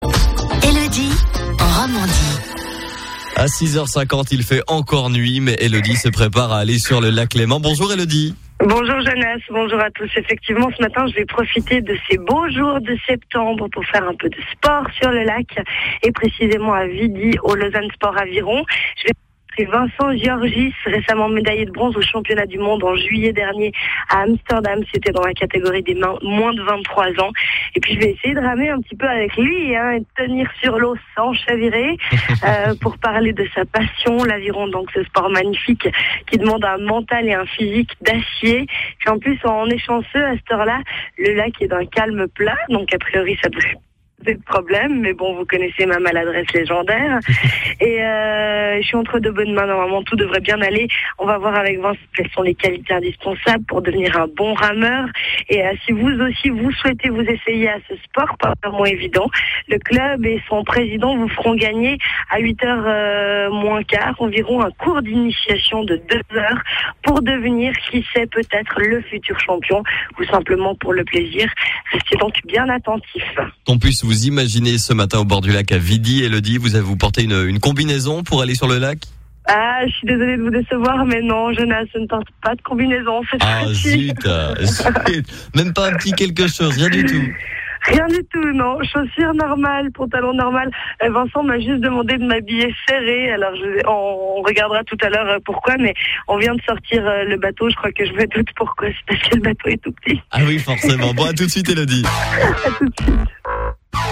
L'interview est en 4 parties.